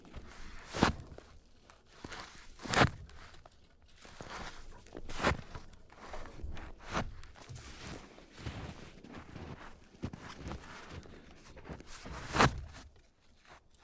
瑞士 " 大量的树枝被折断，特写，H6
描述：录制在“sächsischeSchweiz”中。用Zoom H6（Mic：XYH6）录制41khz / 16bit
标签： 烂木 开裂 裂缝 分支 桩的分支 桩木 休息 变焦H6 树木折断 断裂 树枝 特写
声道立体声